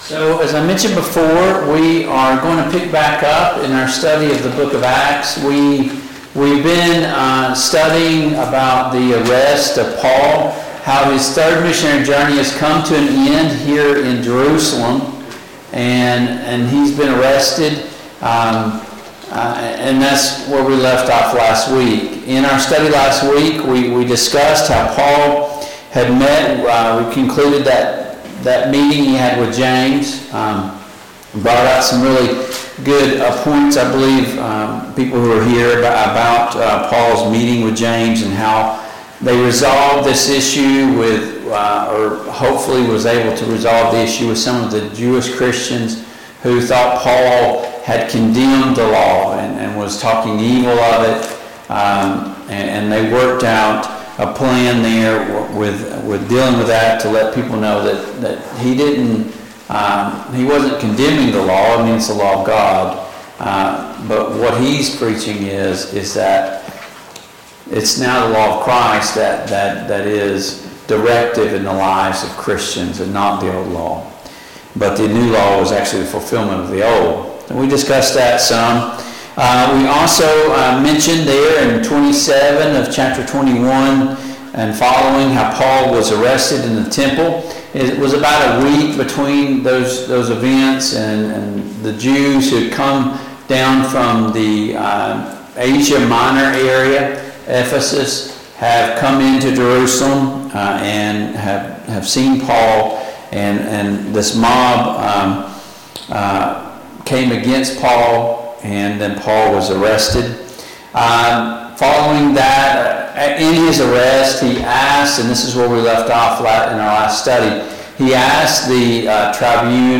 Passage: Acts 22:3-30; Acts 23:1-5 Service Type: Mid-Week Bible Study